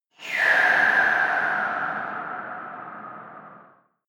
Gemafreie Sounds: Lüfter und Ventilatoren